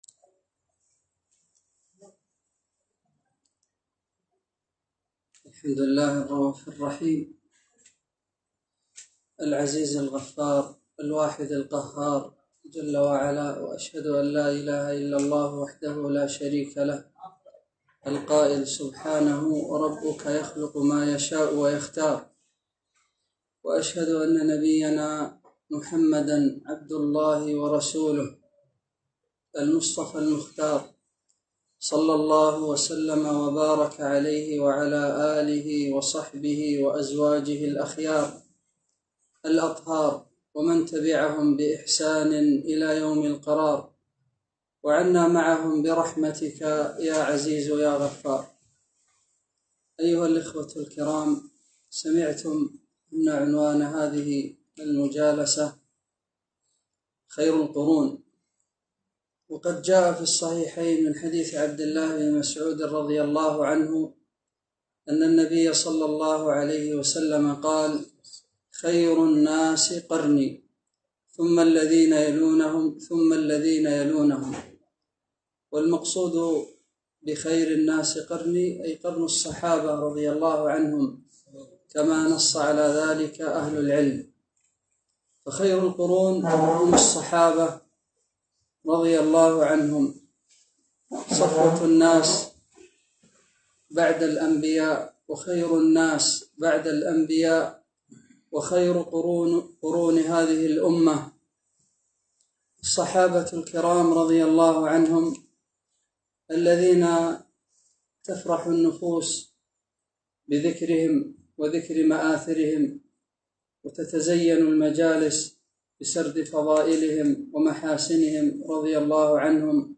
محاضرة - خير القرون - دروس الكويت